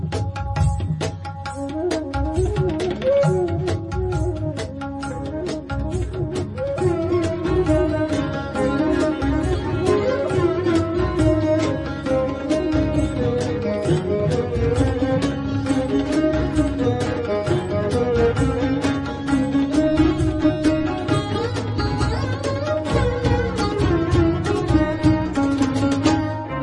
Classical Ringtones